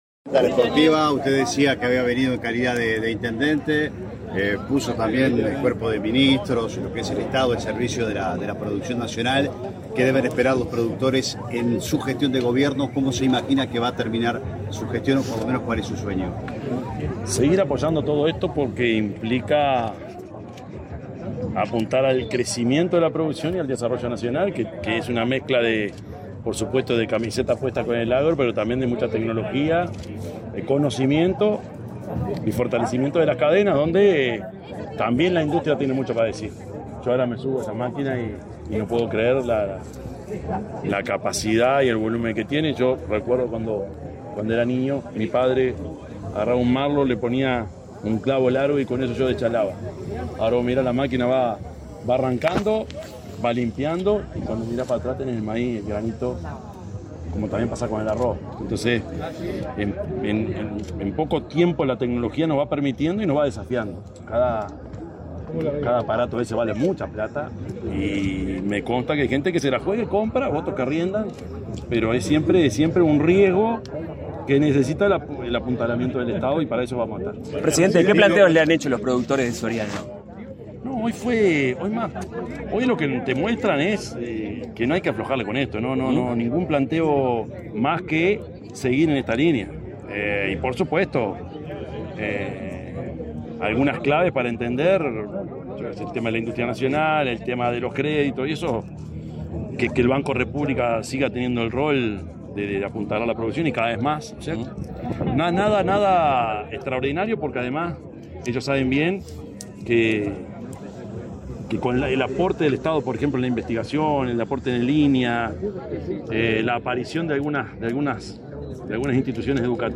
Declaraciones a la prensa del presidente de la República, Yamandú Orsi
Declaraciones a la prensa del presidente de la República, Yamandú Orsi 19/03/2025 Compartir Facebook X Copiar enlace WhatsApp LinkedIn Tras participar en la inauguración de la 28.° Expoactiva, en el departamento de Soriano, este 19 de marzo, el presidente de la República, profesor Yamandú Orsi, realizó declaraciones a la prensa.